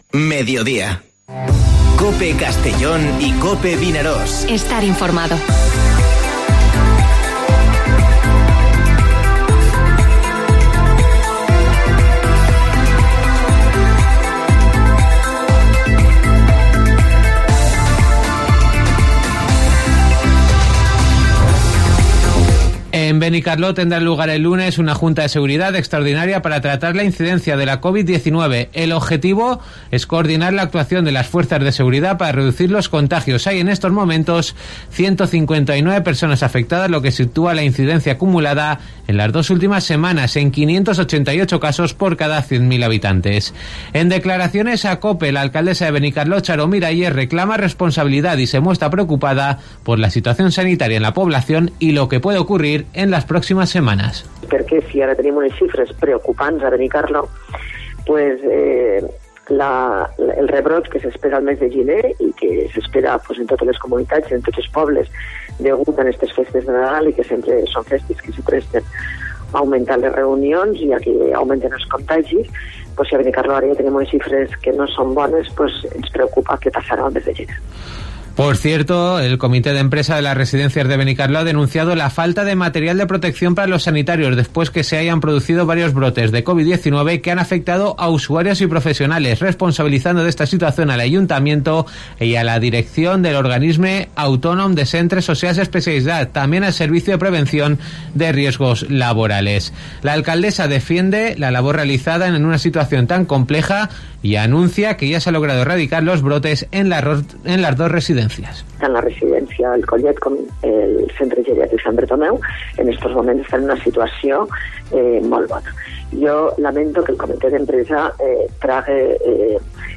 Informativo Mediodía COPE en la provincia de Castellón (17/12/2020)